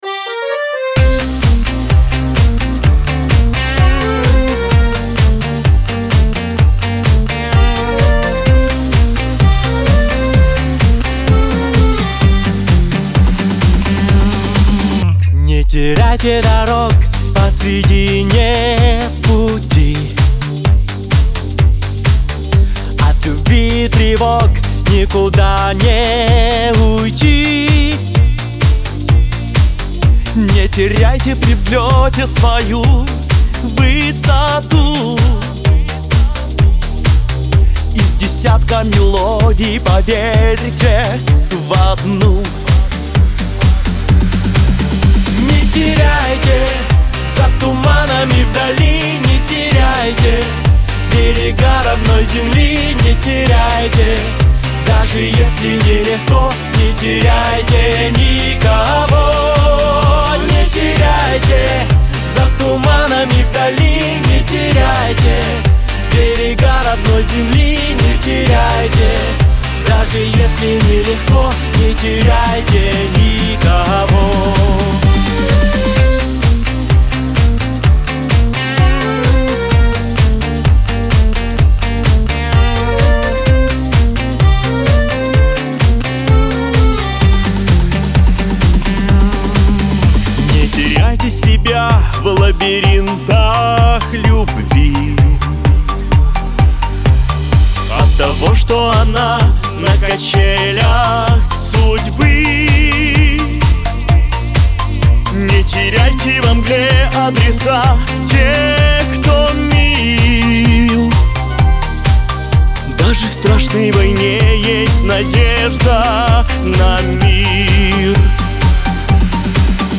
песня прозвучала на славянском базаре 2022